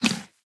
哞菇：进食
玩家喂食棕色哞菇时随机播放这些音效
Minecraft_mooshroom_eat3.mp3